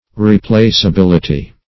Replaceability \Re*place`a*bil"i*ty\ (-?-b?l"?-t?), n.